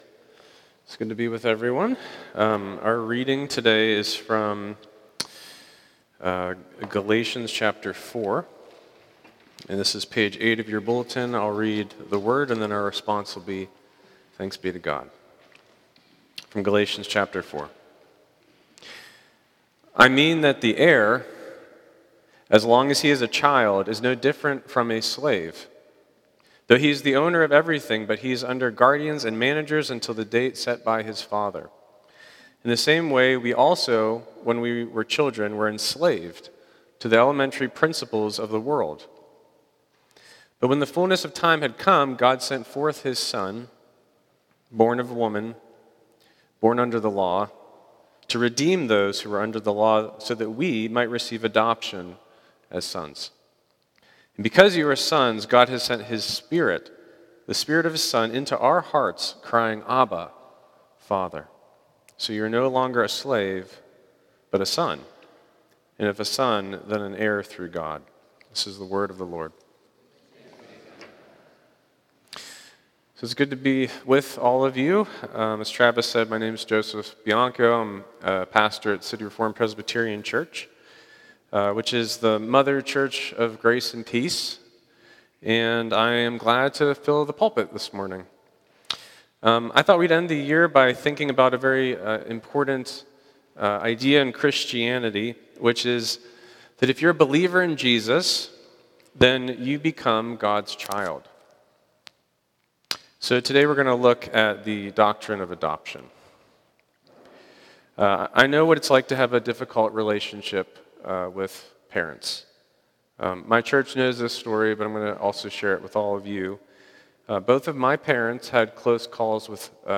Weekly sermons from Grace & Peace PCA in Pittsburgh, PA.